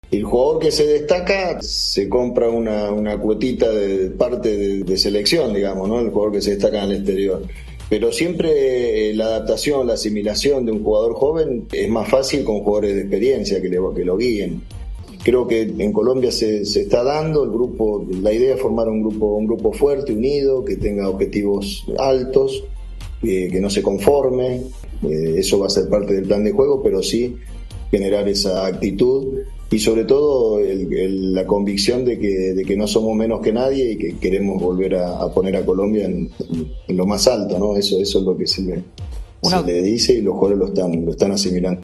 (Néstor Lorenzo, DT del Rayo Vallecano, en diálogo con ESPN)